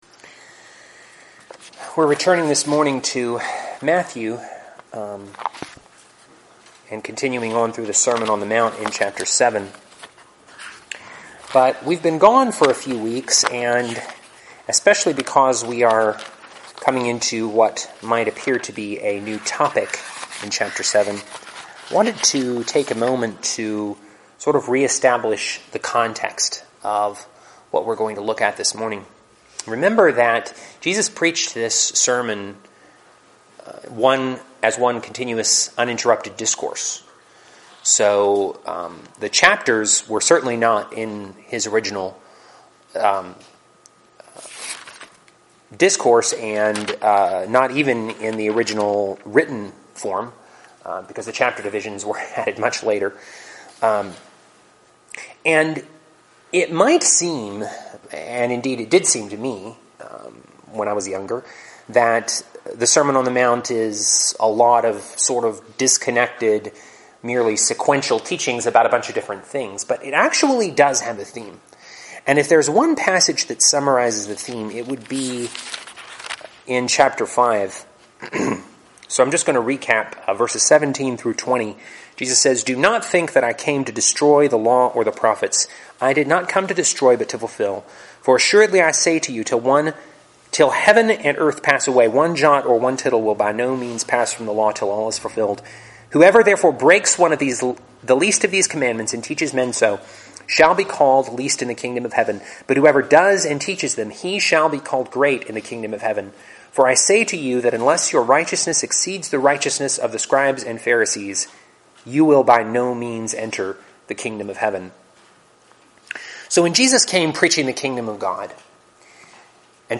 Teachings, Audio Sermons | Hebron Christian Fellowship